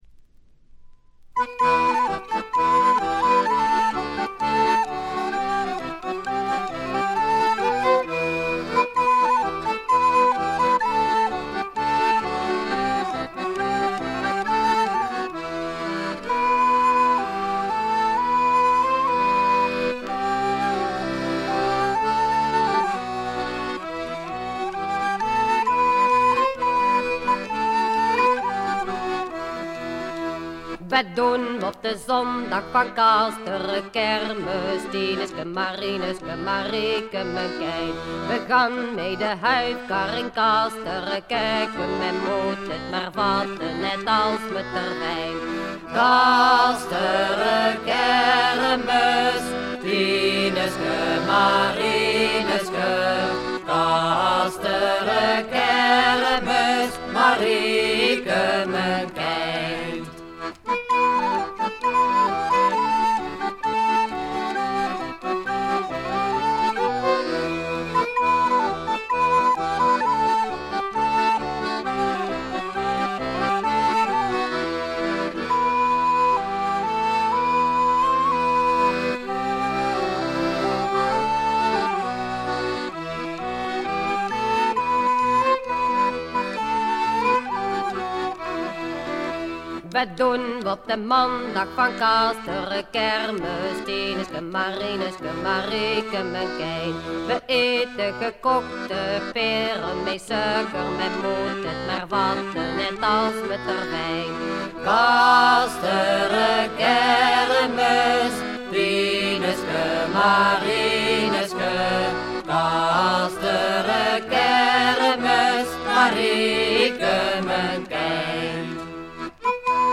気付いたのはB4終了後の曲間にプツ音2回。
オランダのトラッド・バンド、男2女2の4人組。
試聴曲は現品からの取り込み音源です。